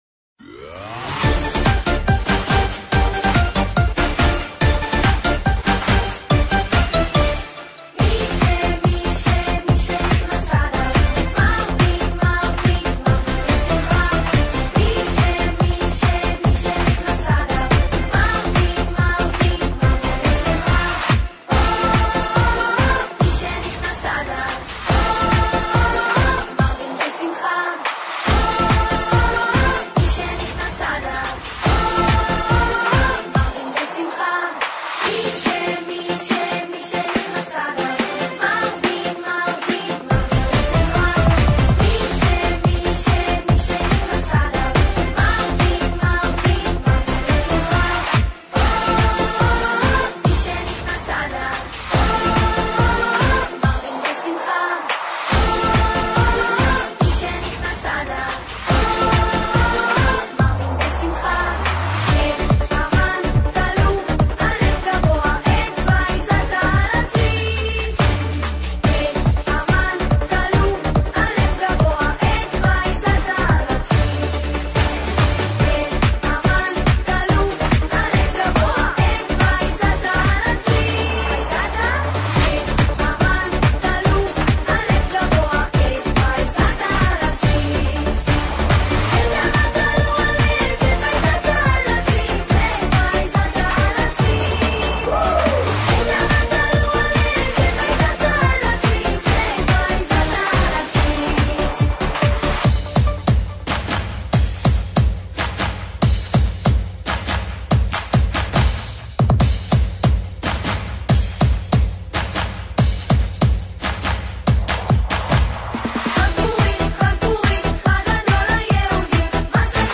Пуримные песни и мелодии - Будь еврейской женщиной
Отмечаете ли Вы Пурим в кругу семьи или в компании – ничто не поднимает настроение так, как подходящая веселая музыка!